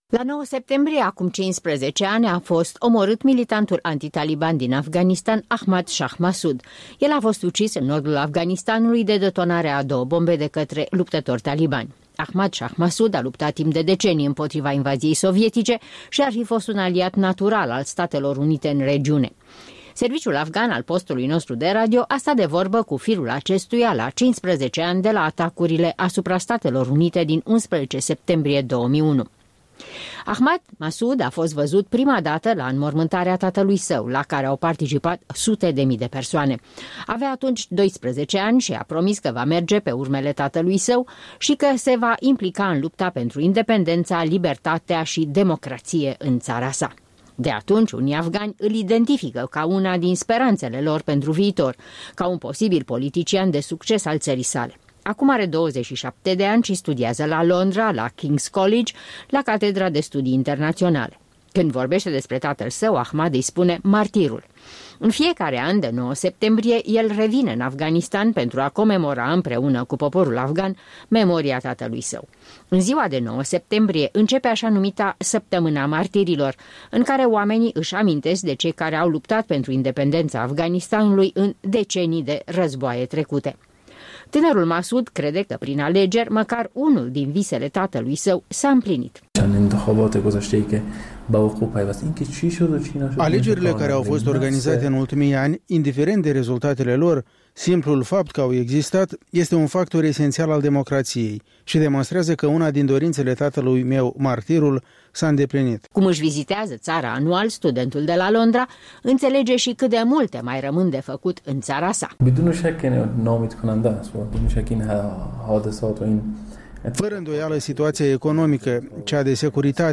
Un interviu cu fiul unui legendar comandant militar al forțelor ce s-au opus talibanilor.